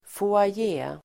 Ladda ner uttalet
Folkets service: foajé foajé substantiv, foyer Uttal: [foaj'e:] Böjningar: foajén, foajéer Synonymer: entré, hall, lobby Definition: förhall Sammansättningar: teaterfoajé (theatre foyer), hotellfoajé (hotel foyer)